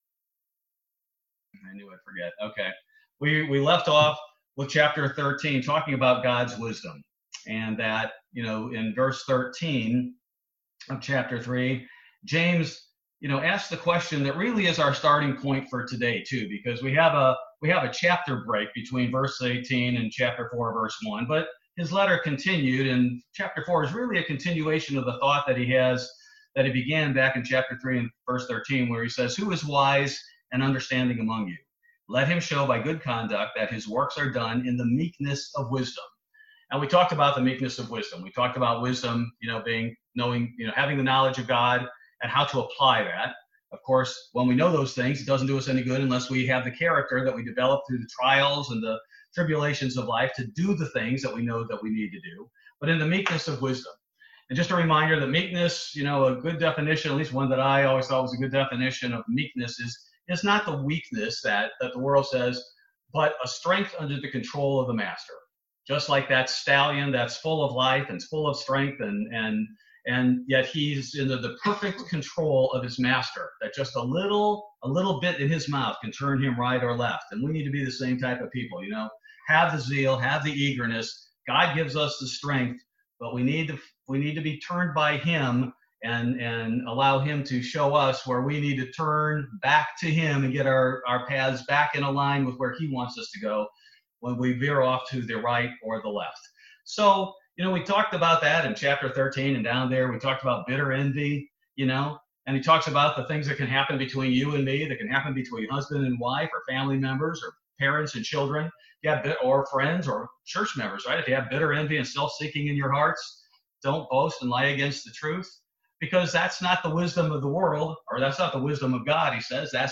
Weekly Bible Study. This study covers the rest of Chapter 3 and Chapter 4 of the Book of James